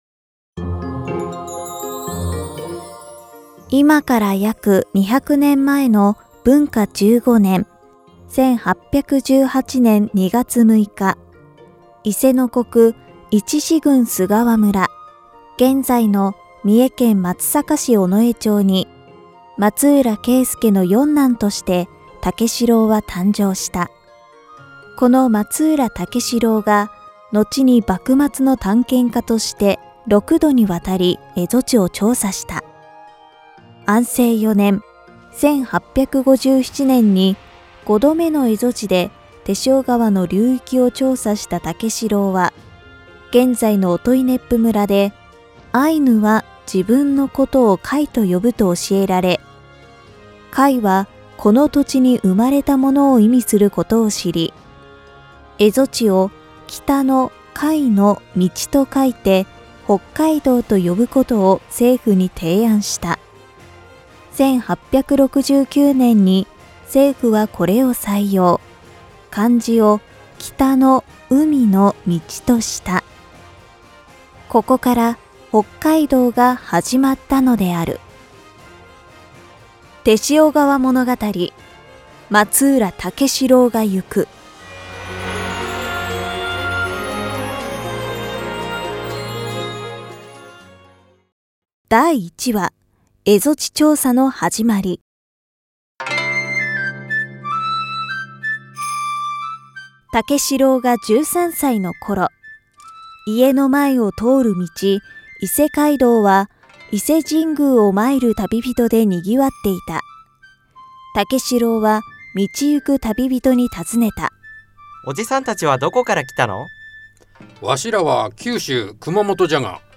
なぜ蝦夷地の調査を行うようになったのか。ドラマと解説で探ってみましょう。